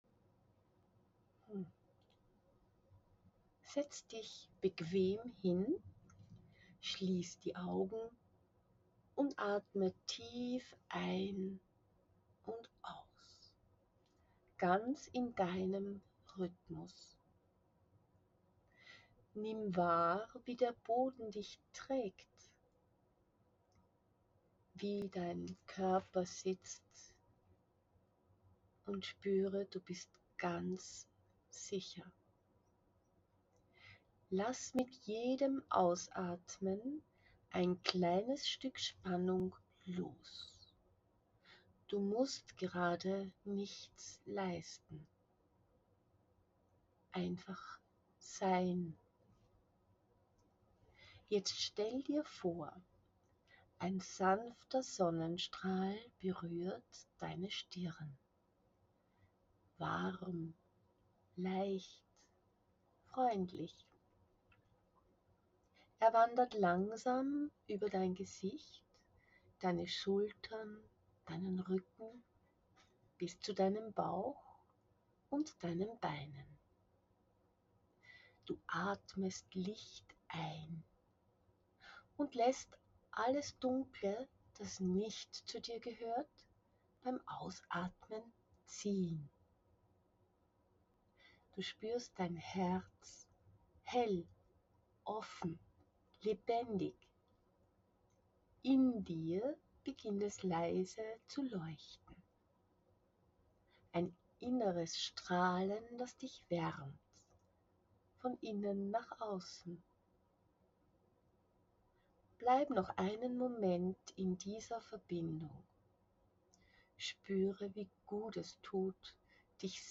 Eine etwa 2,5-minütige Mini-Meditation, die dich aus dem Trubel zurück zu dir holt.
In dieser kurzen, geführten Meditation …